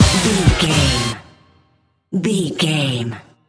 Fast paced
Ionian/Major
Fast
synthesiser
drum machine